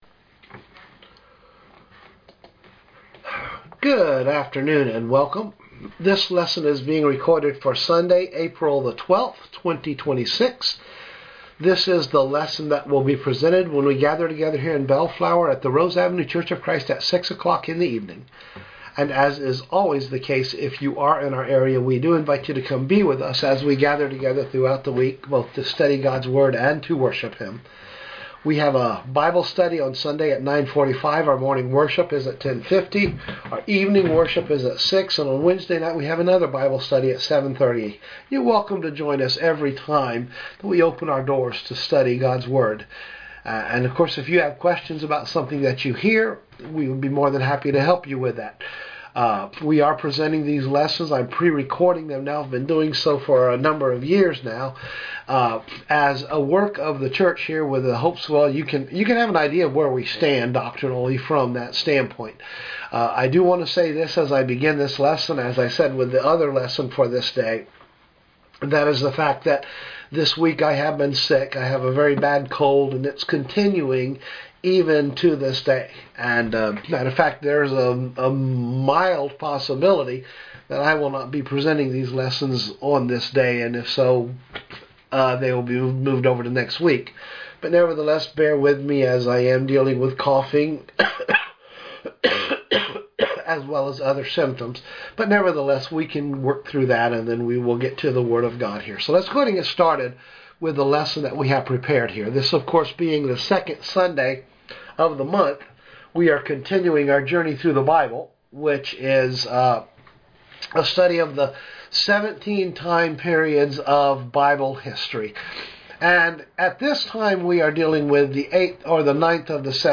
I recorded this lesson on 4/11 and you can tell I have a very bad cold.
Thank you for your patience and understanding with my voice.
Sermon